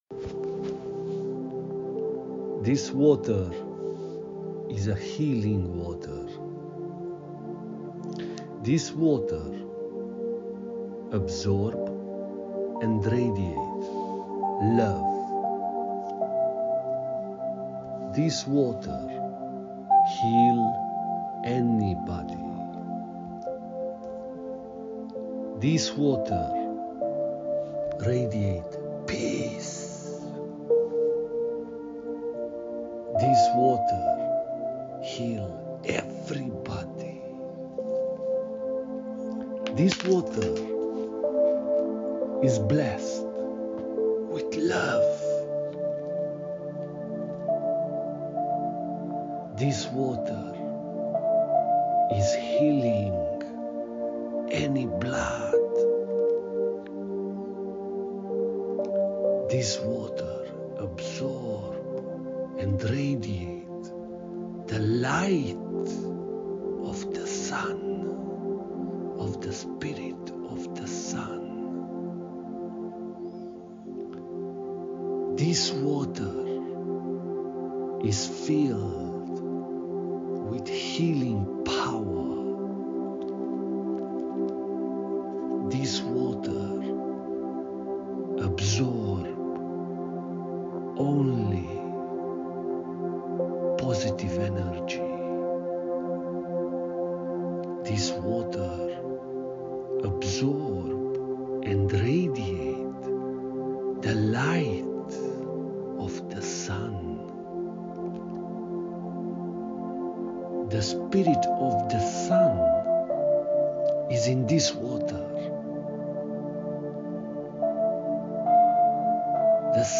Sound healing